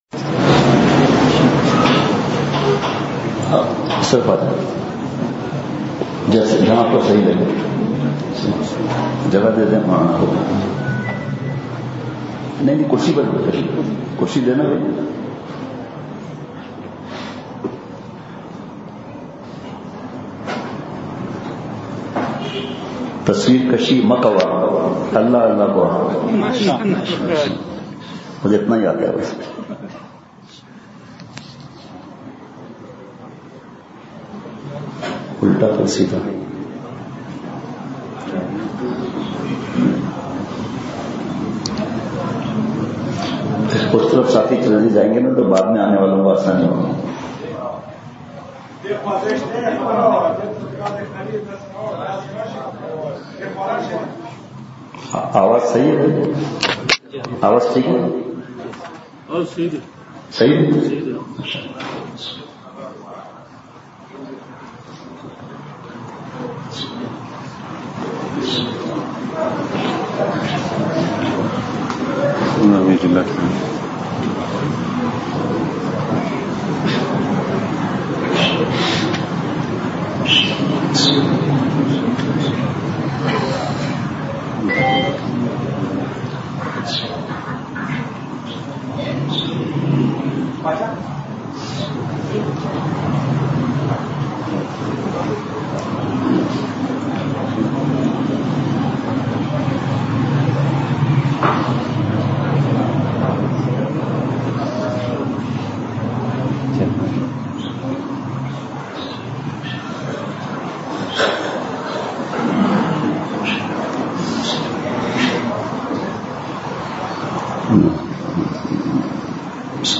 وقت: بعد نماز مغرب بیان روتے ہوئے بہت ہی پرجوش بیان بیان کے بعض نکات تصویر کشی حرام ہے بہت بڑا گناہ ہے۔
دل کو تھاما ان کا دامن تھام کے ہاتھ اپنے دونوں نکلے کام کے بیان کے اخر میں درد بھری دعا ہوئی۔